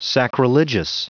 Prononciation du mot sacrilegious en anglais (fichier audio)
Prononciation du mot : sacrilegious